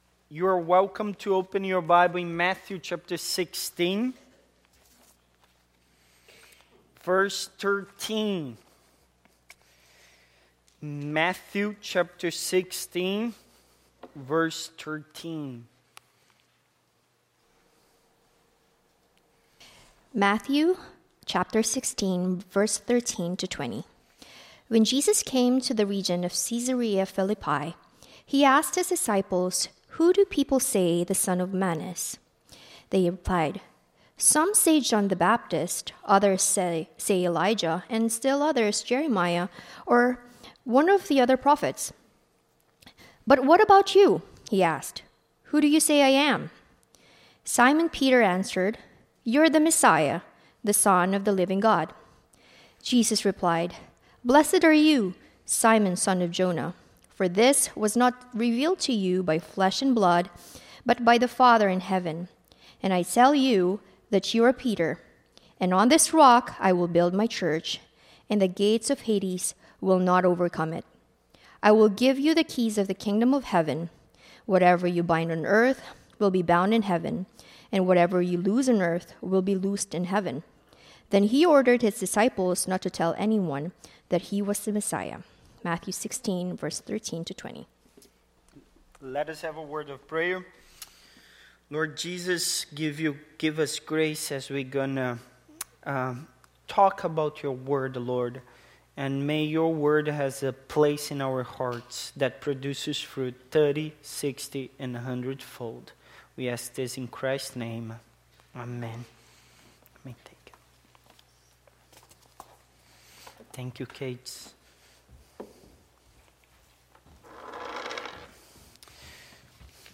Passage: Matthew 16:13-20 Service Type: Sunday Morning